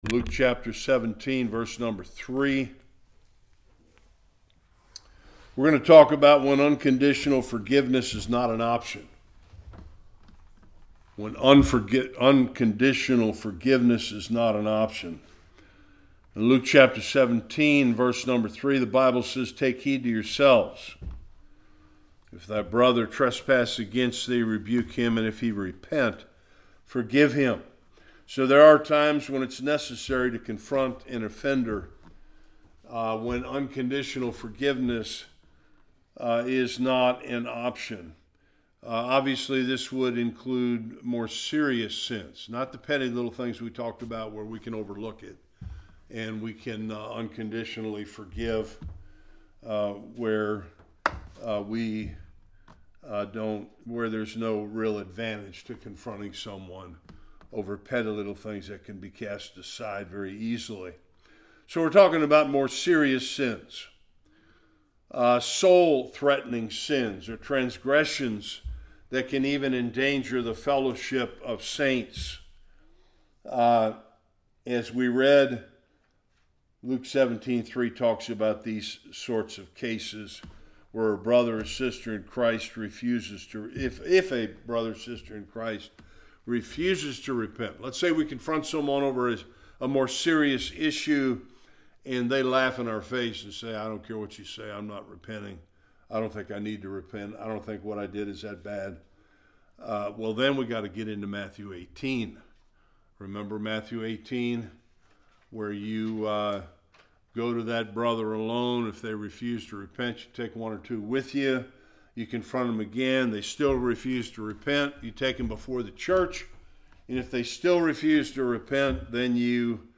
Various Passages Service Type: Bible Study There are times when the only option is to confront an offender.